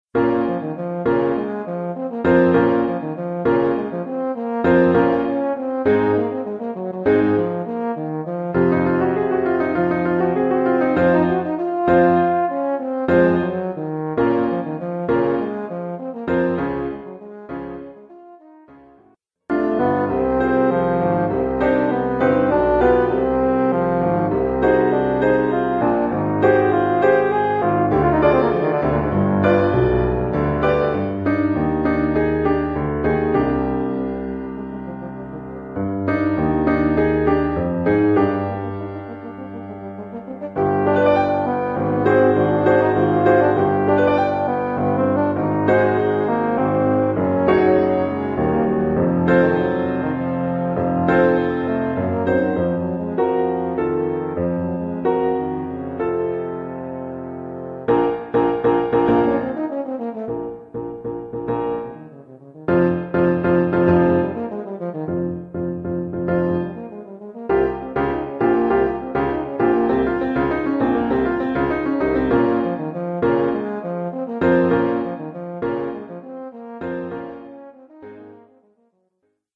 Oeuvre pour saxhorn ténor sib
ou euphonium sib et piano.
(instrument avec accompagnement de piano et piano seul).